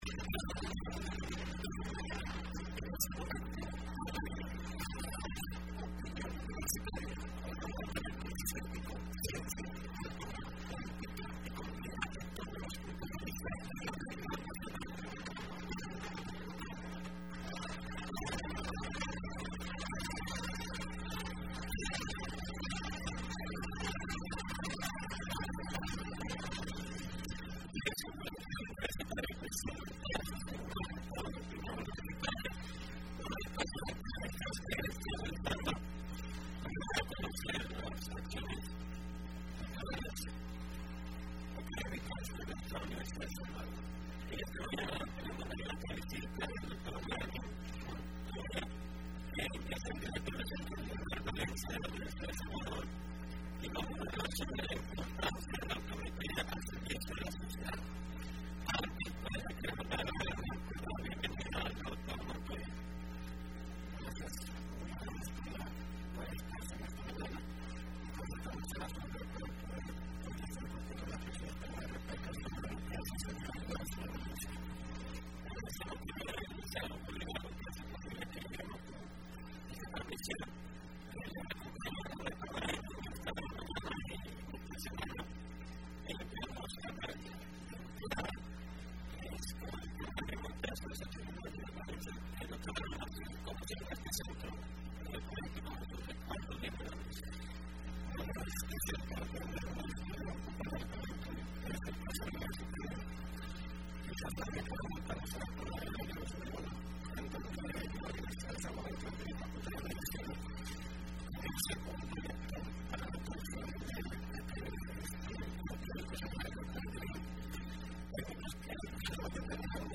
Entrevista Opinión Universitaria (11 marzo 2015): Funciones del Centro Regional Valencia